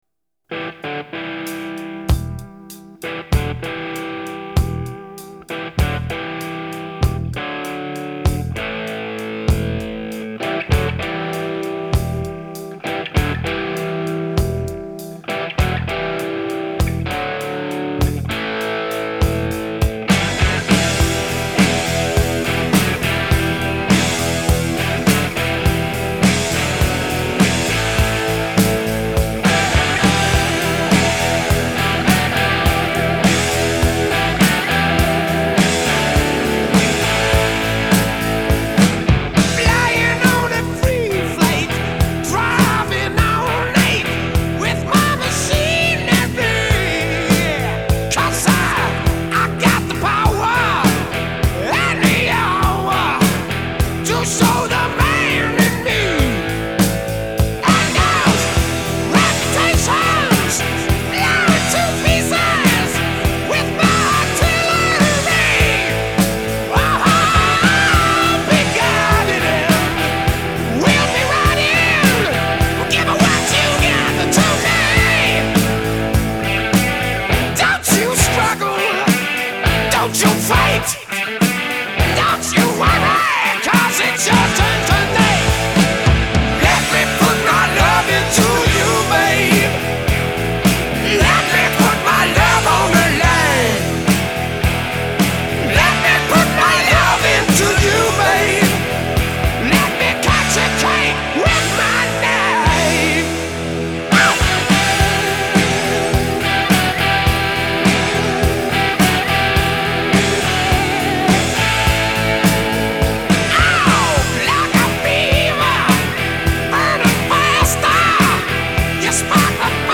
Жанр: Hard Rock, Heavy Metal